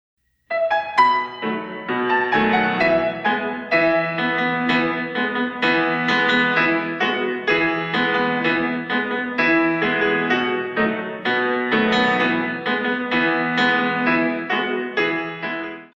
In 2
32 Counts + 8 (balance)